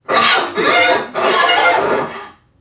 NPC_Creatures_Vocalisations_Robothead [91].wav